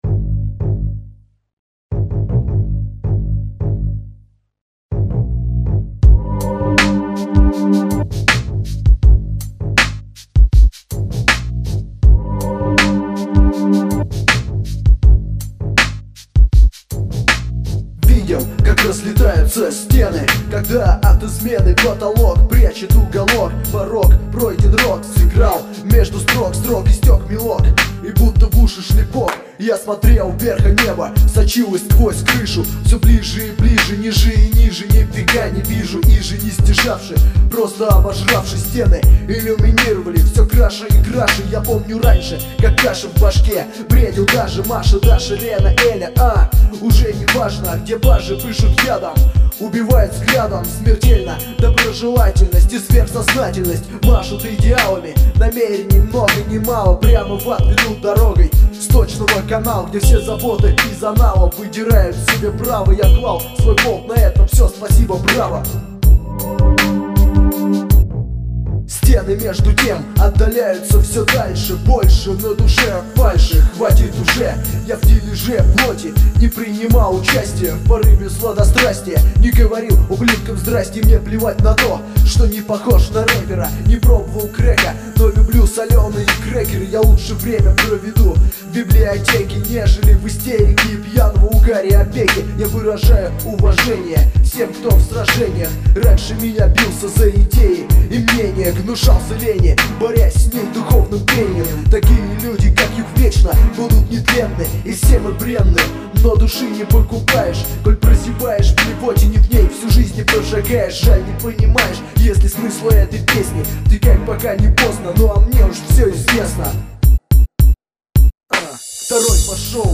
Дерзкий.. Жёсткий.. Правдивый...